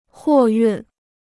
货运 (huò yùn): freight transport; cargo.